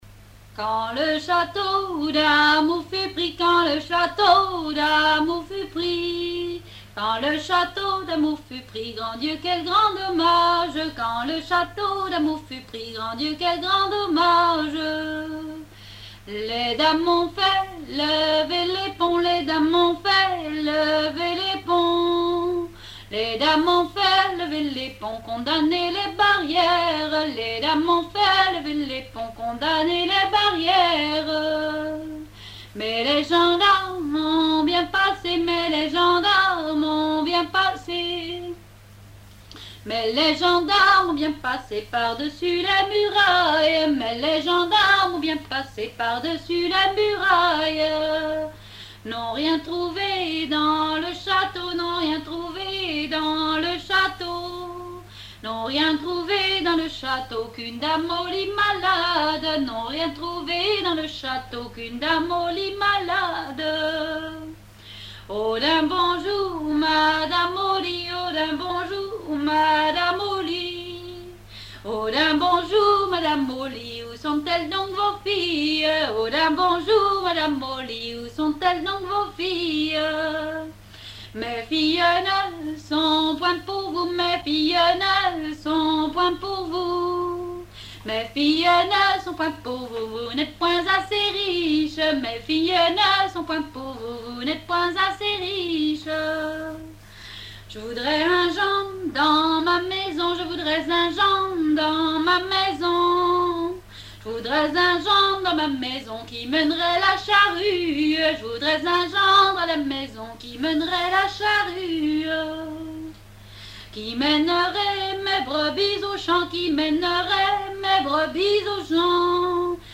danse : ronde : grand'danse
répertoire de chansons traditionnelles
Pièce musicale inédite